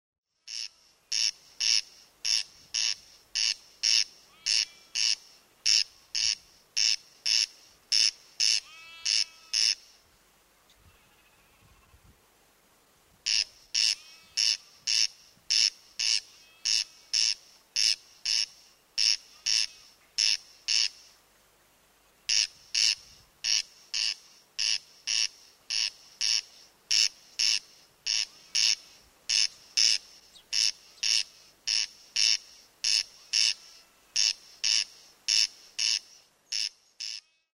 While you’re pondering on that you may want to also have a think about what this eerie noise from the British Library Wildlife Sound Archive is made by:
…and it’s not the sound of a tiny wild clown chasing sheep in the dead of night.